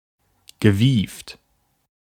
Ääntäminen
Ääntäminen US : IPA : [ˈkʌ.nɪŋ]